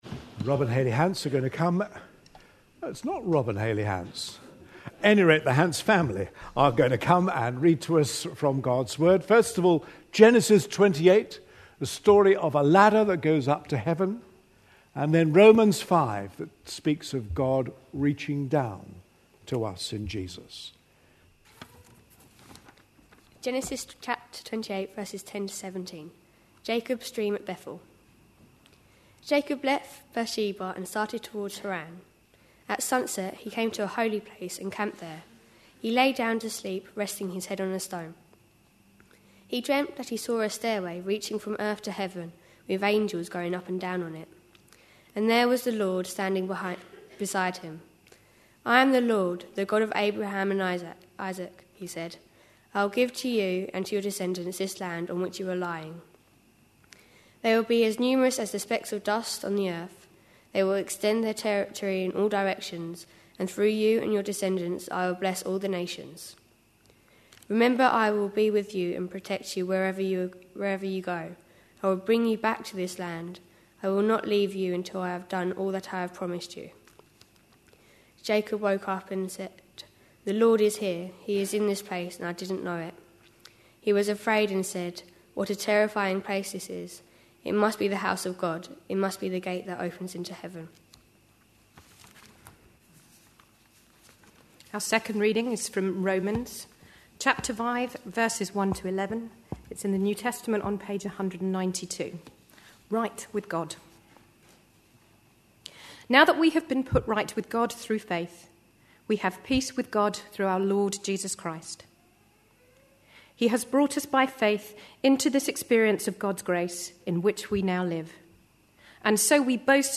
A sermon preached on 1st April, 2012, as part of our Looking For Love (10am Series) series.